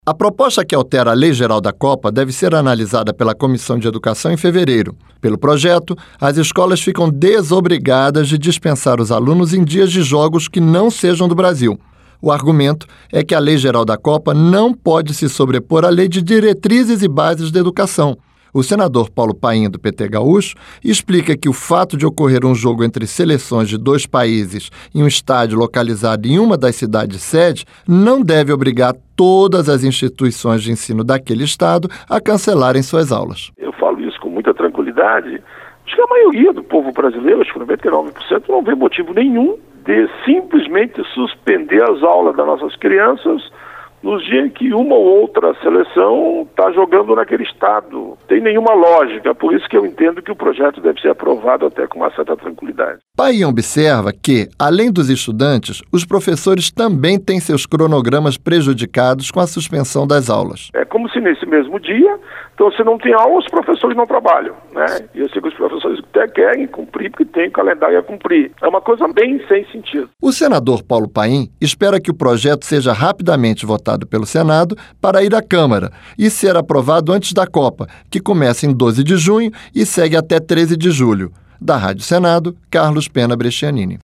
Rádio Senado - Ao Vivo